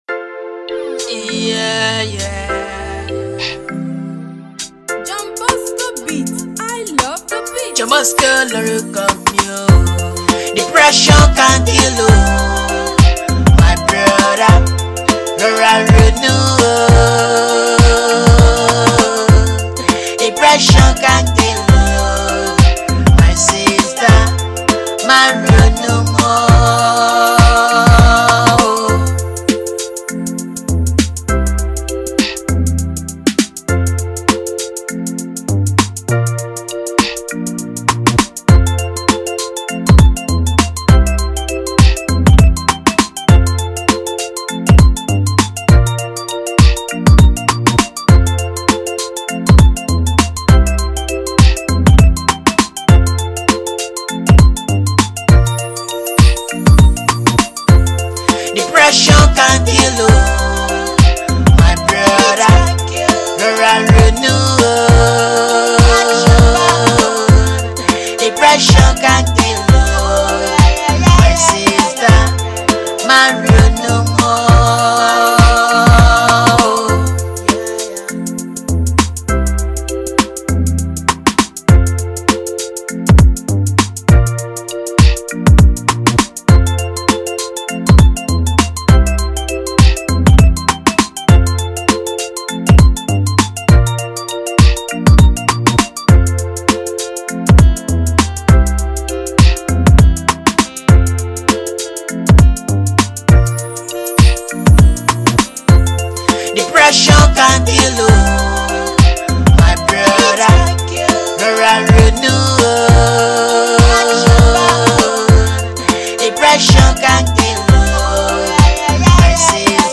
instrumental Free beat + Hook
December 27, 2019 admin Instrumentals 0